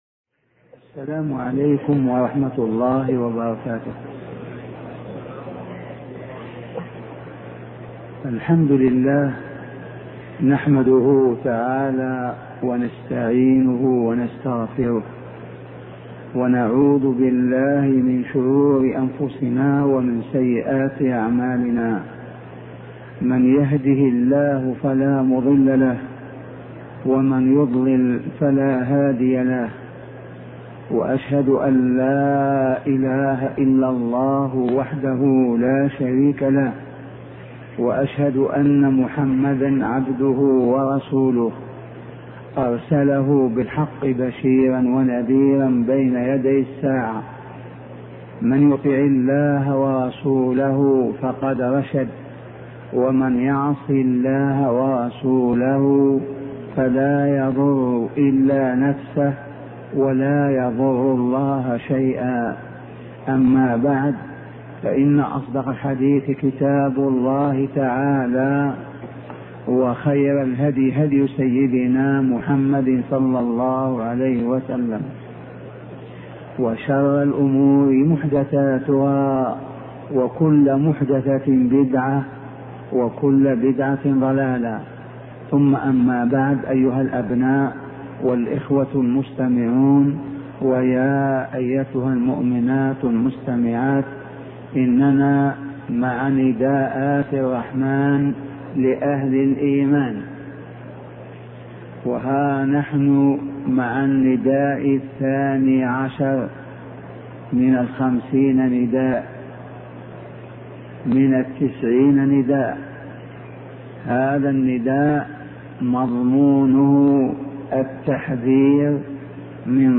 شبكة المعرفة الإسلامية | الدروس | نداءات الرحمن لأهل الإيمان 012 |أبوبكر الجزائري
مدرس بالمسجد النبوي وعضو هيئة التدريس بالجامعة الإسلامية سابقا